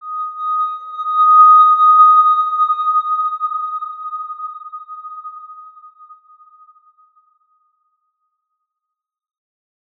X_Windwistle-D#5-pp.wav